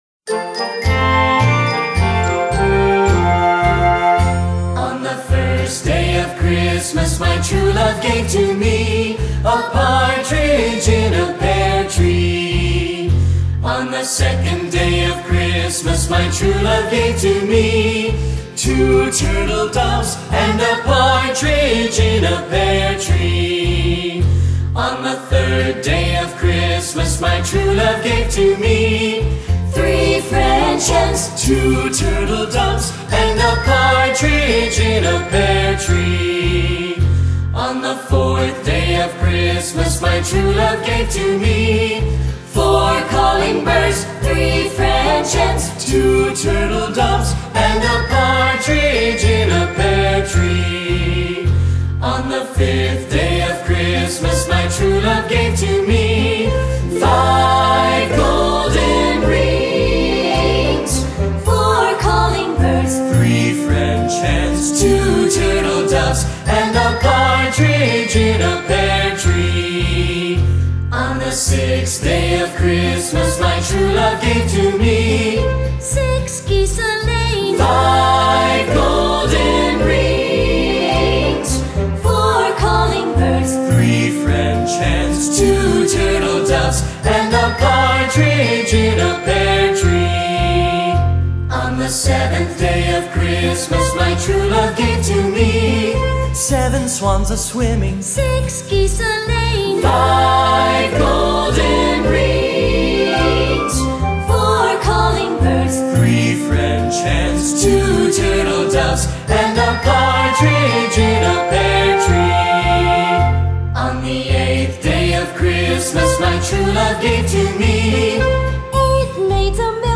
Traditional Christmas Song